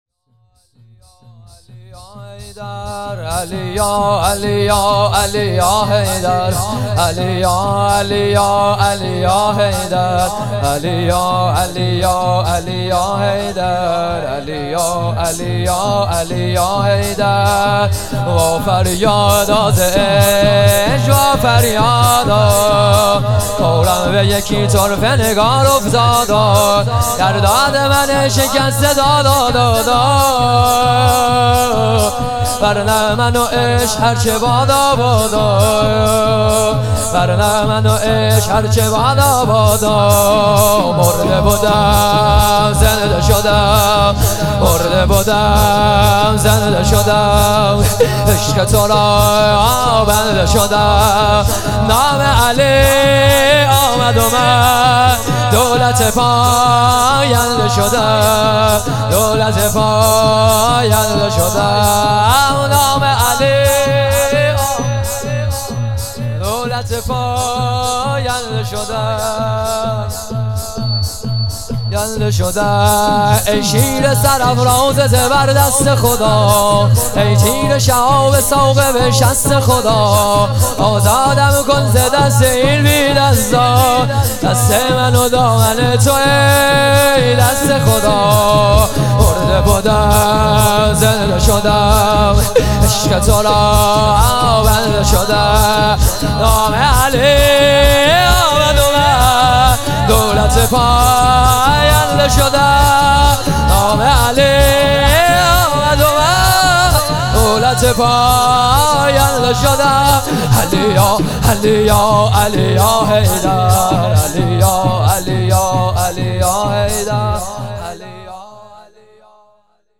هیئت فرهنگی مذهبی فاطمیون درق